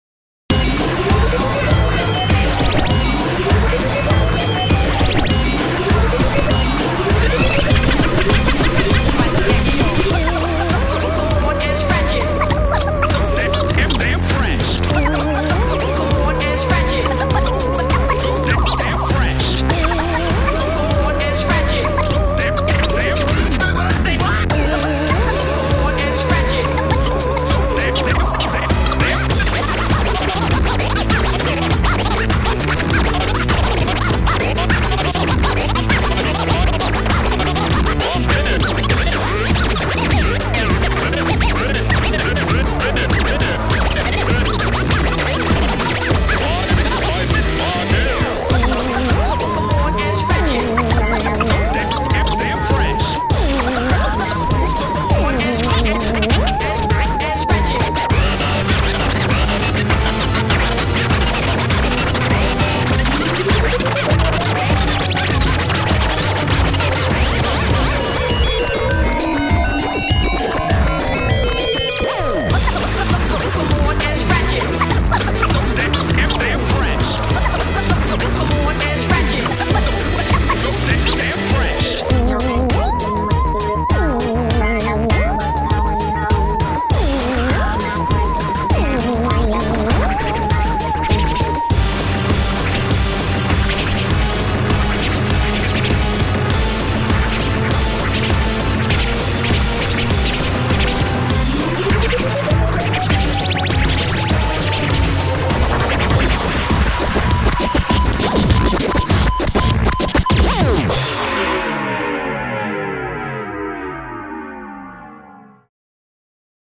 BPM100-200
Audio QualityPerfect (High Quality)
Enjoy that scratching.